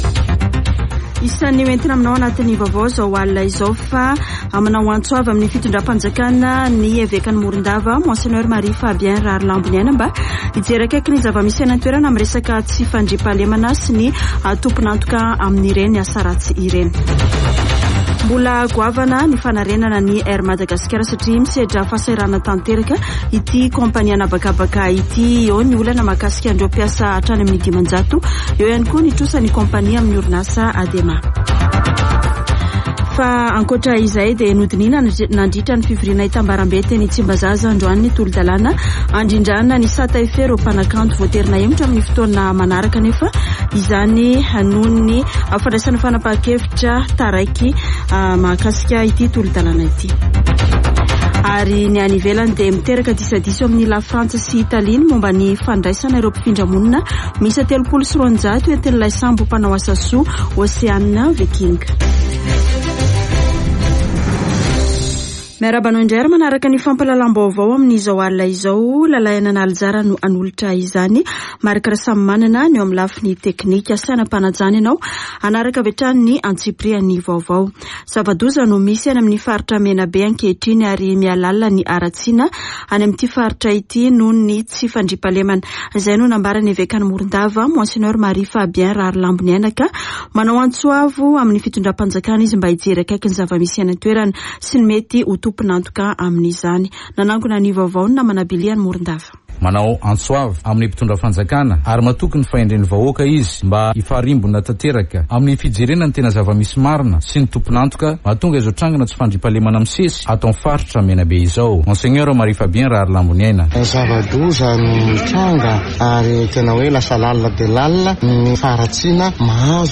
[Vaovao hariva] Alatsinainy 14 nôvambra 2022